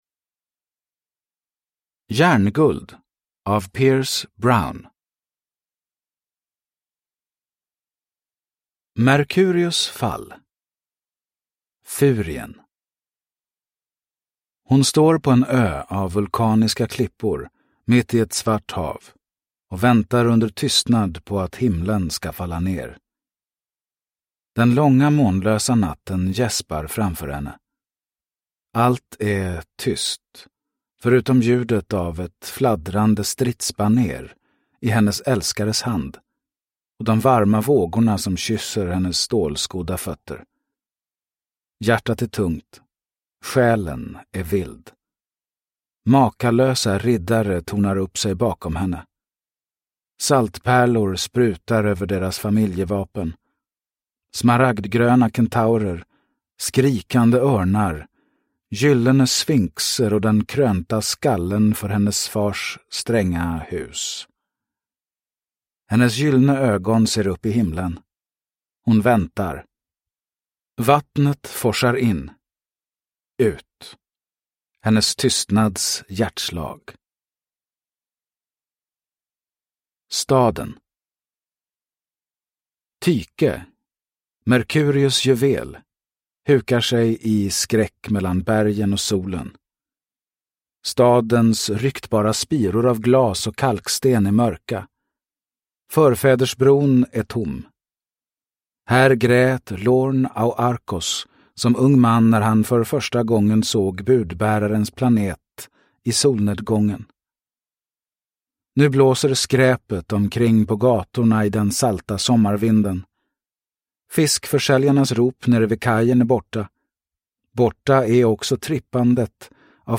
Järnguld – Ljudbok – Laddas ner